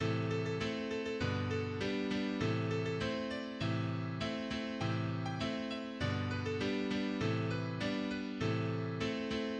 "Shady Grove" (Roud 4456)[1] is a traditional Appalachian folk song,[2] believed to have originated in eastern Kentucky around the beginning the 20th century.[3] The song was popular among old-time musicians of the Cumberlands before being widely adopted in the bluegrass repertoire.[4] Many variants of "Shady Grove" exist (up to 300 stanzas by the early 21st century).[5]
The Dorian mode melody was first published as "Shady Grove" in the Journal of American Folklore in 1915,[8] but it was traditionally used in Appalachia for the ballad Matty Groves, as sung by traditional singers including Sheila Kay Adams ("Lady Margaret")[9] and Dillard Chandler ("Mathie Groves").